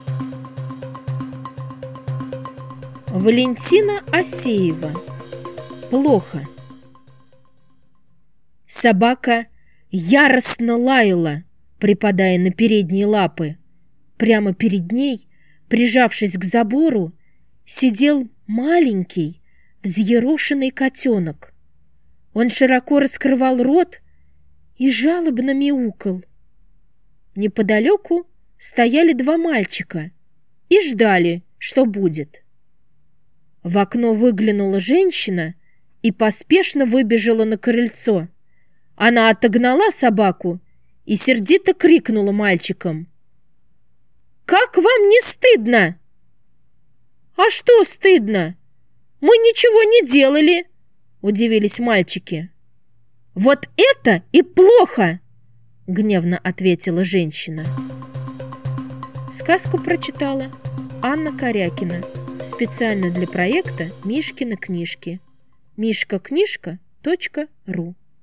Плохо - аудио рассказ Осеева В. Рассказ про то, что иногда плохим поступком может стать, когда ты просто стоишь и ничего не делаешь.